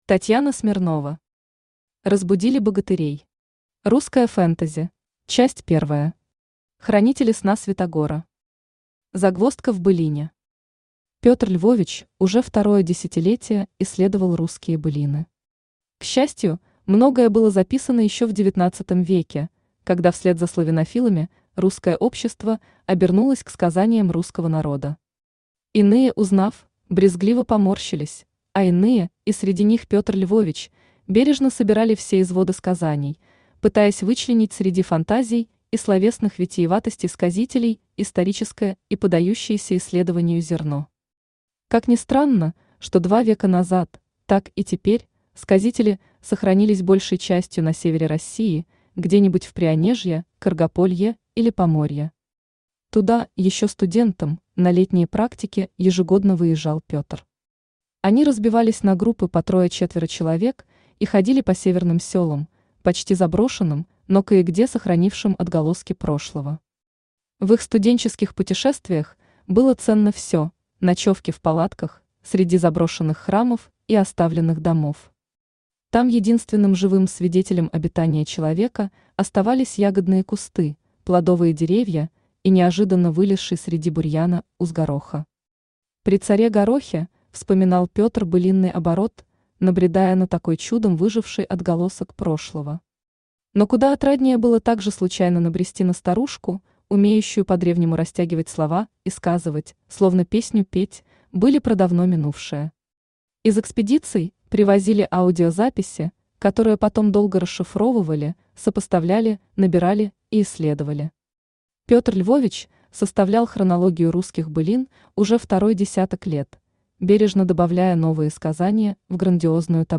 Аудиокнига Разбудили богатырей. Русское фэнтези | Библиотека аудиокниг
Русское фэнтези Автор Татьяна Андреевна Смирнова Читает аудиокнигу Авточтец ЛитРес.